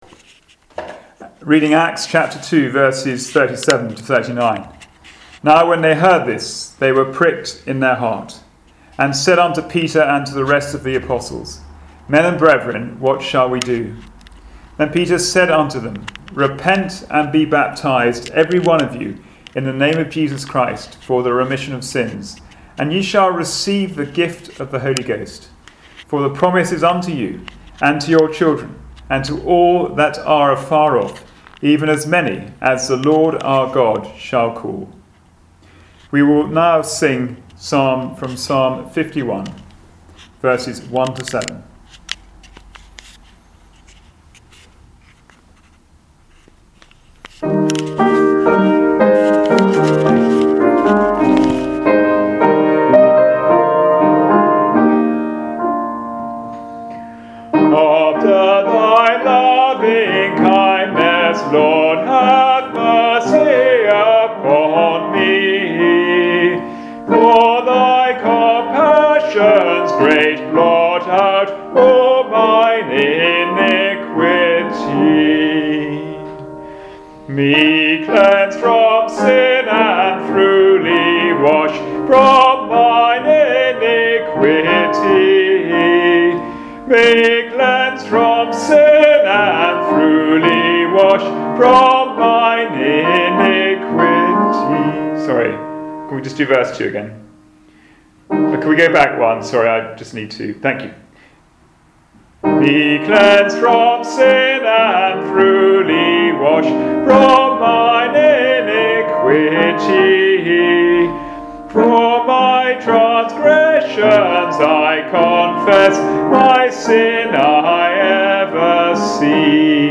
Service Type: Sunday Evening Service